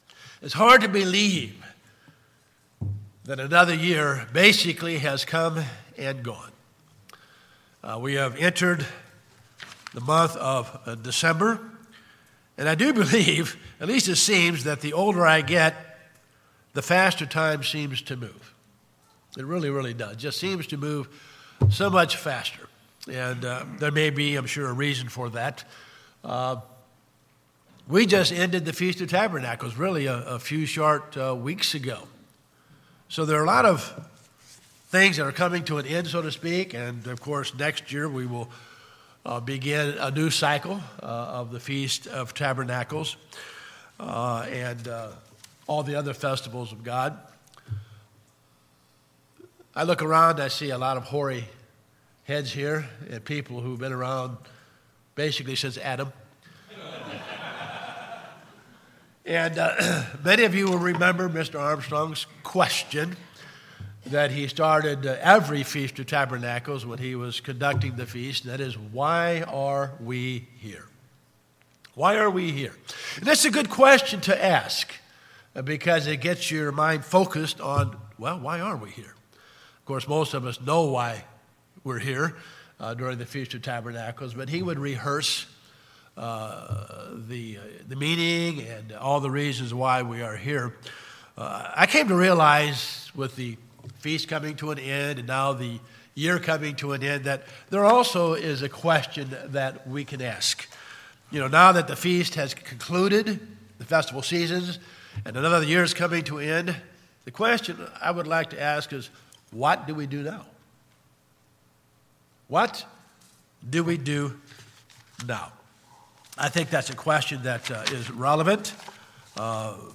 Given in East Texas